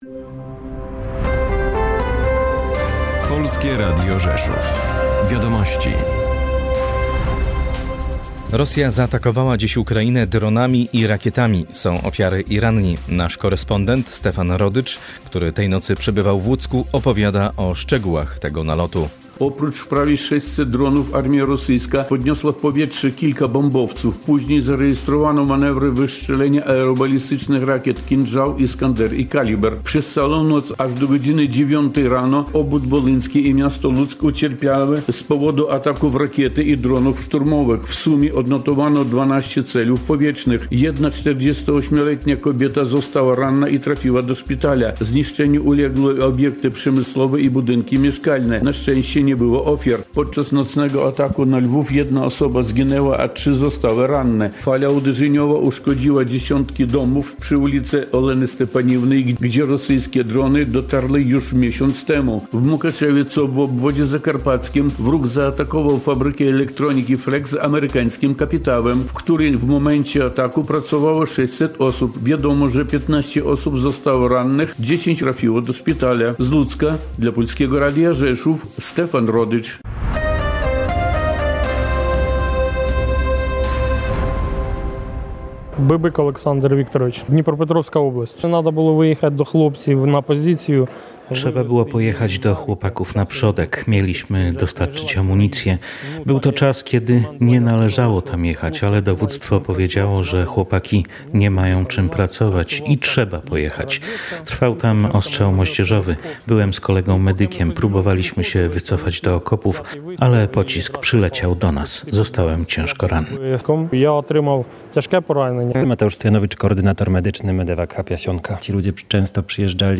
Spotkanie z reportażem – „Ludzie Tytani”